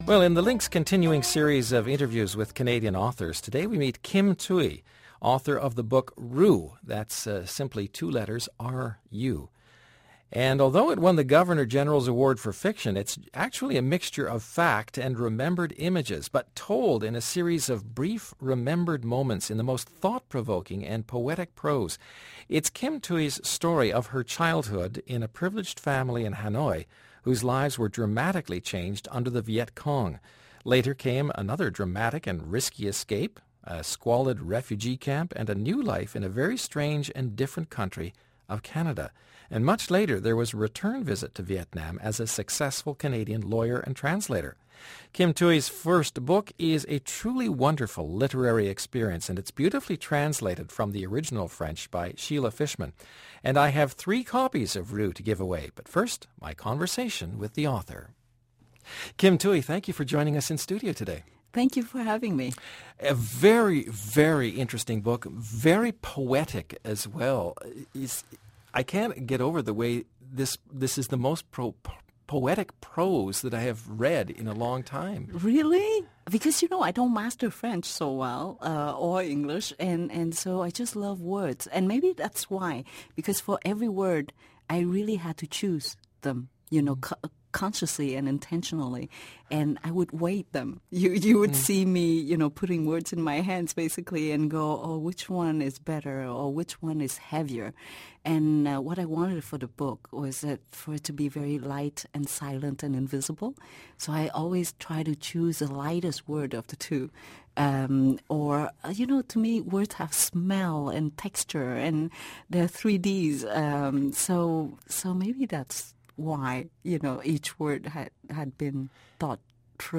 Feature-interview-Kim-Thuy-author-of-Ru.mp3